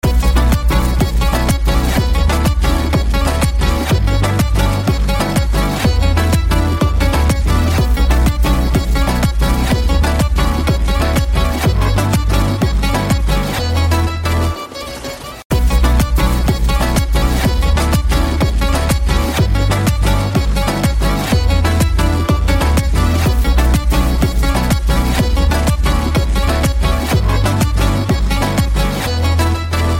tamil ringtonedance ringtoneparty ringtonesouth ringtone
best flute ringtone download